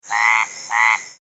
Lowland Burrowing Treefrog - Smilisca fodiens